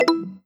dialog-cancel-select.wav